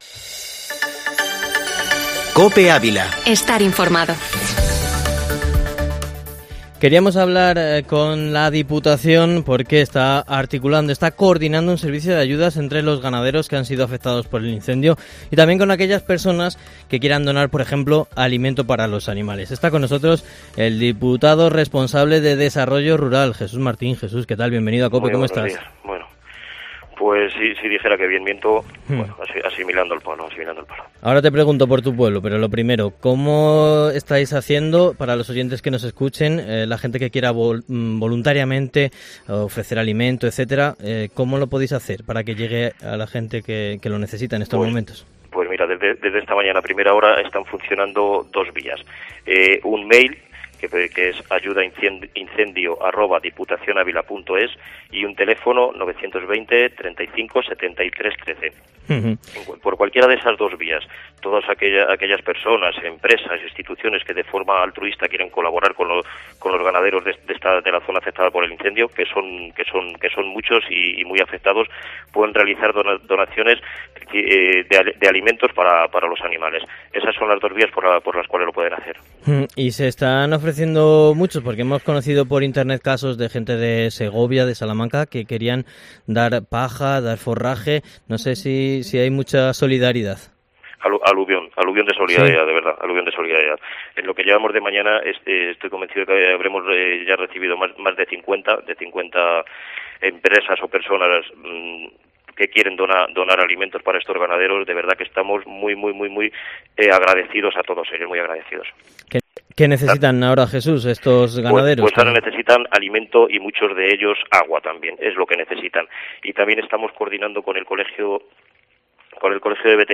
Entrevista en COPE con Jesús Martín, alcalde de Solosancho y diputado de Desarrollo Rural -17-agosto